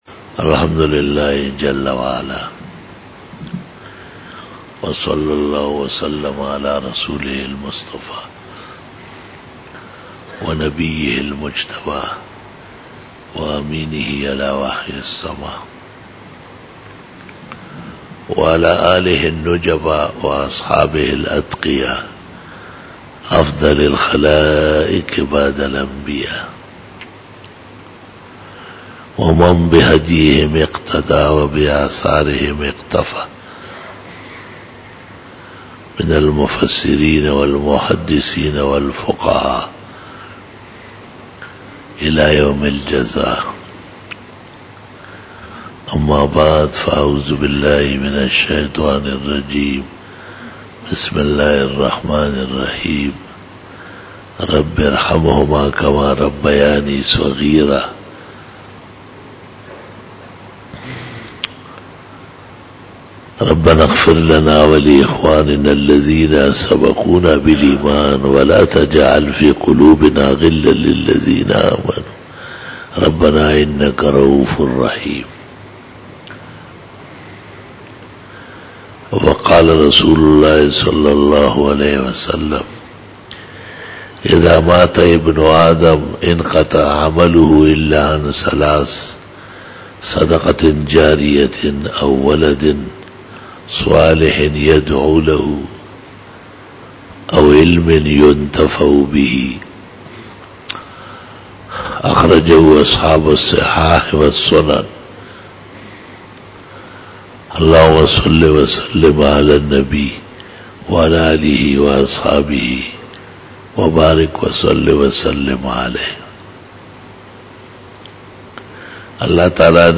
08 BAYAN E JUMA TUL MUBARAK 24 FEBRUARY 2017 (26 Jamadi ul Awwal 1438H)
Khitab-e-Jummah 2017